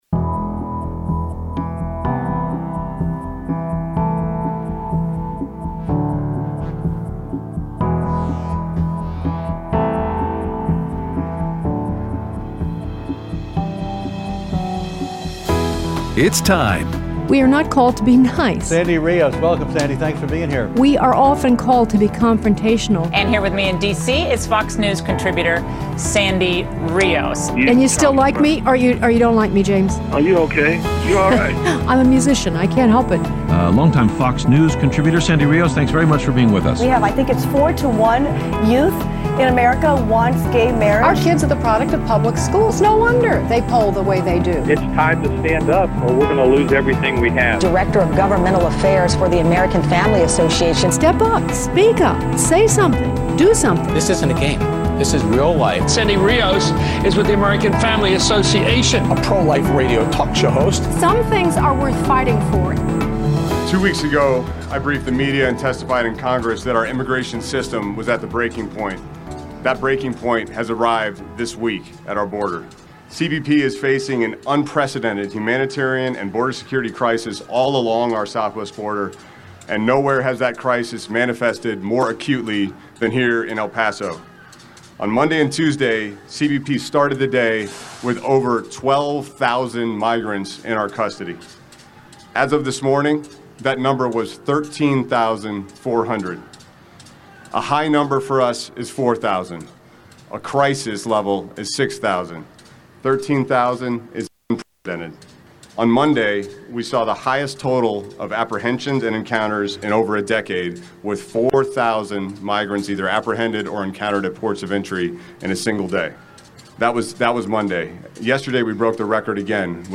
Aired Thursday 3/28/19 on AFR 7:05AM - 8:00AM CST